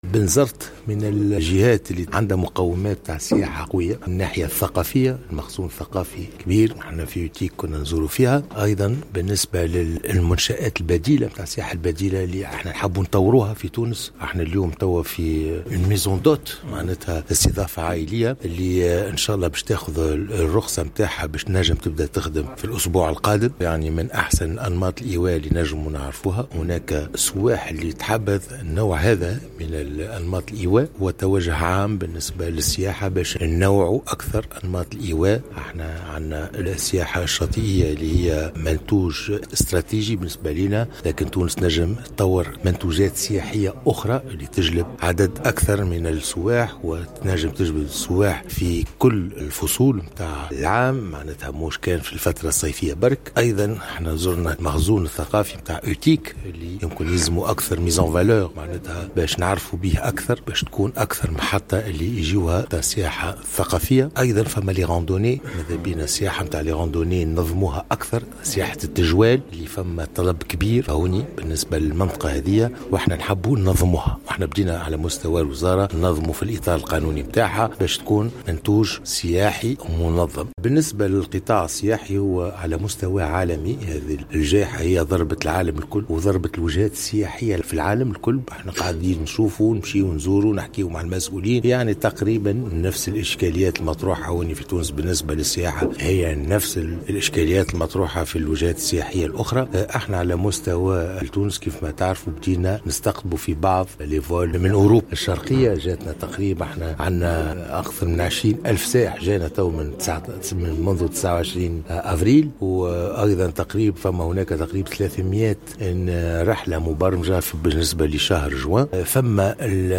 وقال عمار في تصريح إعلامي، بالمناسبة، إن ولاية بنزرت تملك كافة المقومات السياحية عالية الجودة، لاسيما في مجال السياحة البديلة والثقافية والايكولوجية، معتبرا أن تنوع القطاع السياحي بإمكانه أن يسهم في معالجة مختلف الإشكاليات التنموية والاجتماعية بالجهة ككل.